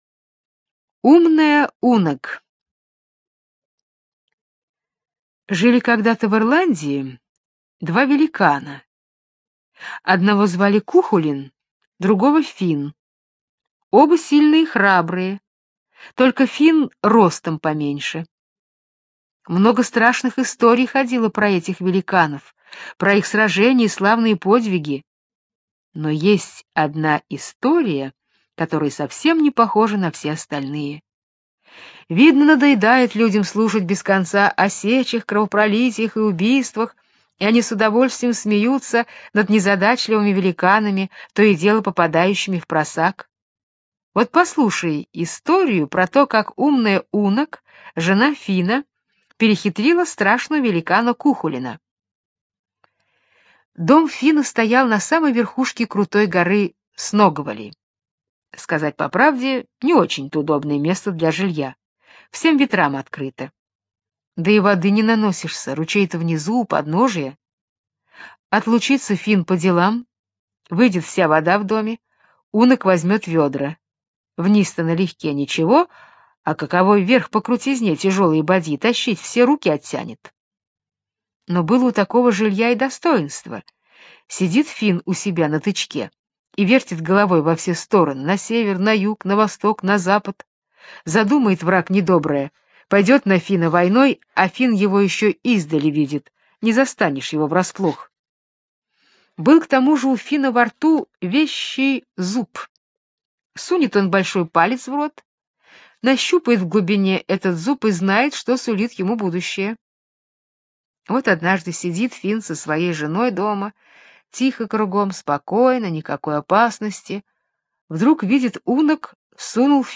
Умная Унаг - британская аудиосказка - слушать онлайн